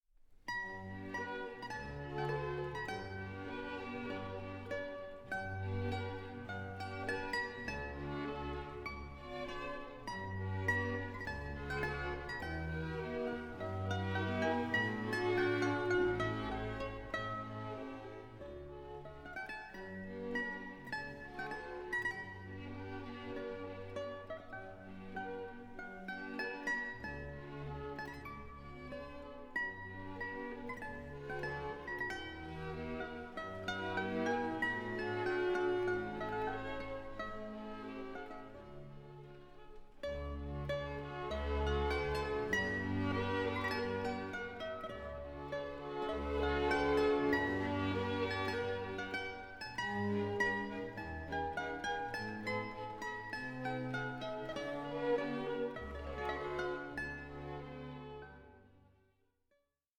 Theme and Variations for Mandolin and Orchestra